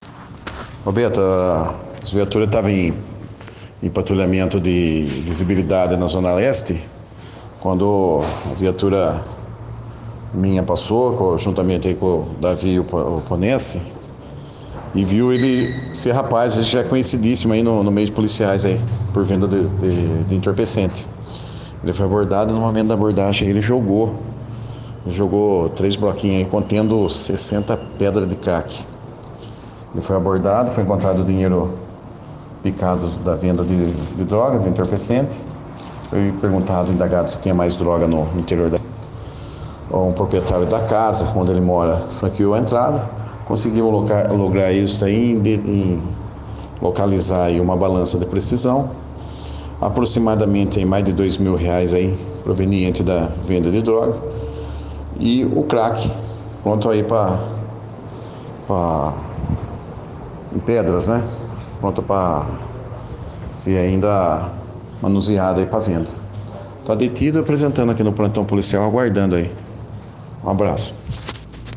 Nossa reportagem esteve conversando com o GCM